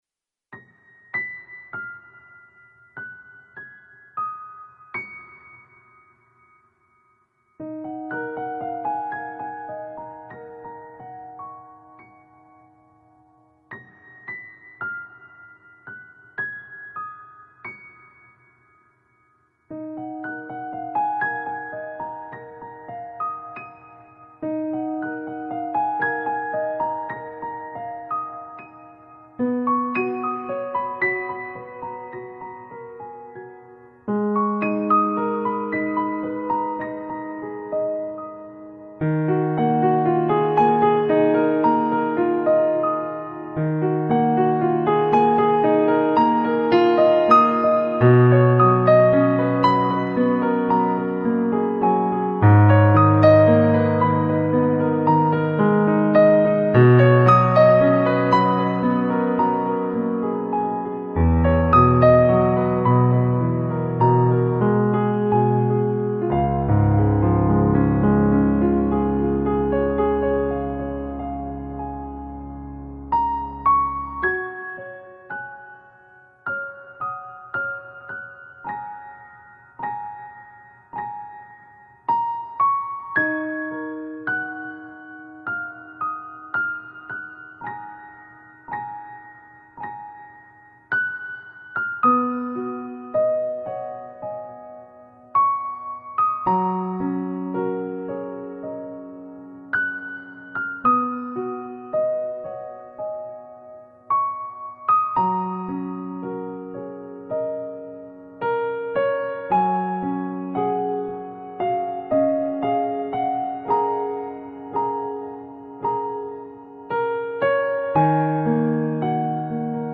трогательная музыка !!!......немножко жаль деревья прощаюшиеся со своими листочками.....трепетно маша им в след тоненькими ручками -веточками......нравится настроение работы !!!